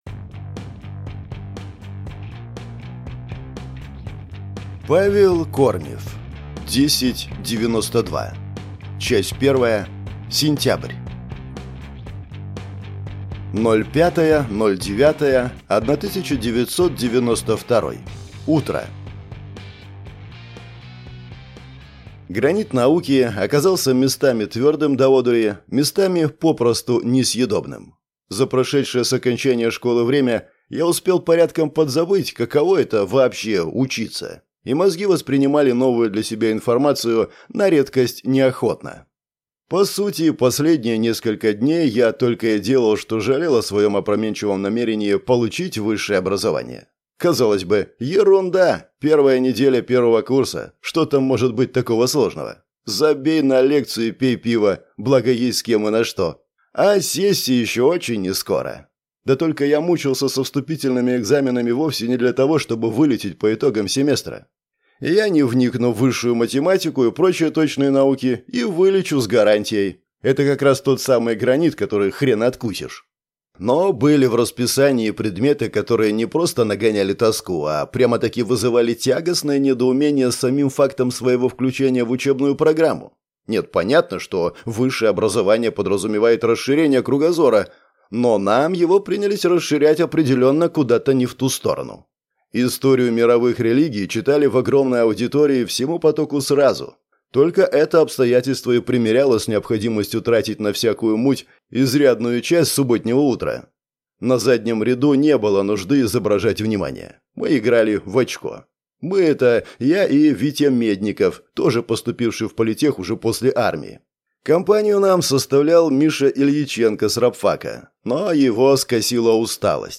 Аудиокнига 10'92 - купить, скачать и слушать онлайн | КнигоПоиск